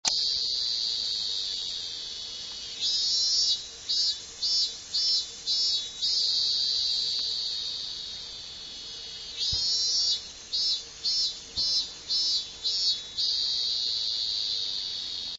robust cicada